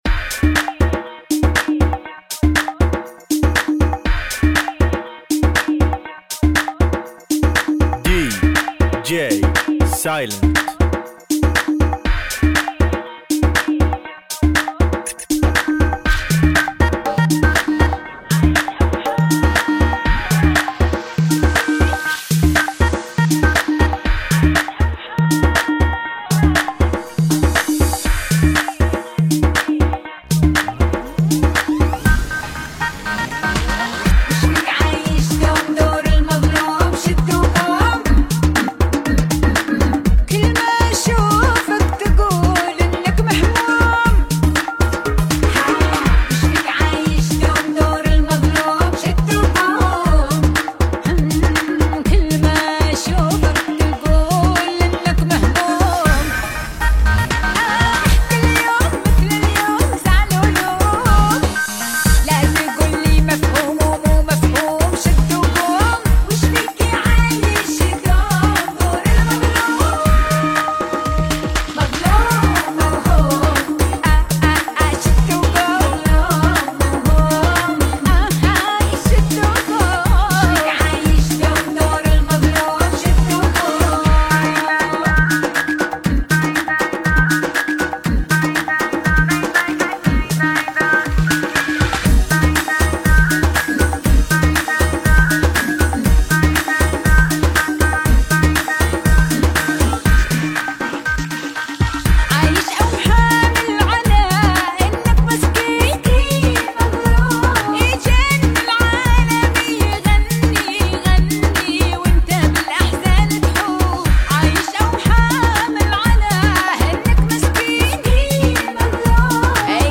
[ Bpm120 ]